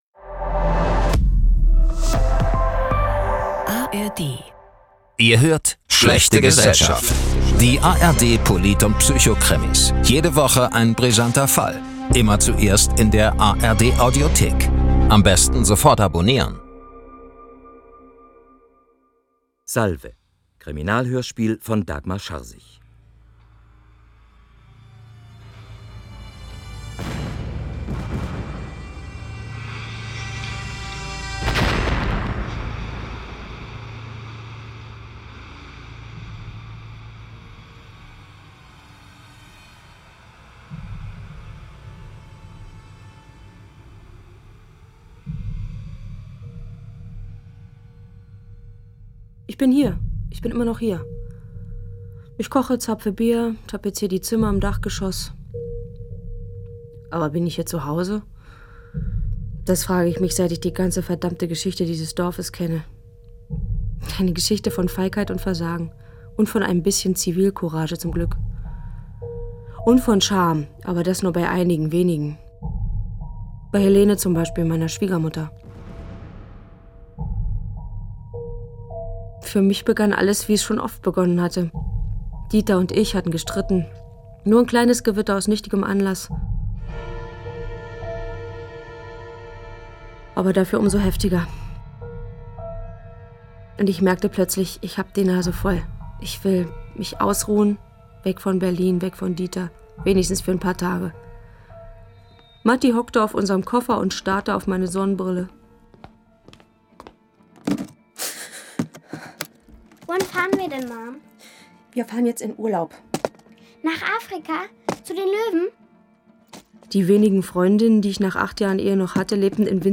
Salve! – Geschichtsdrama in der brandenburgischen Provinz ~ Schlechte Gesellschaft - ARD Polit- und Psychokrimis Podcast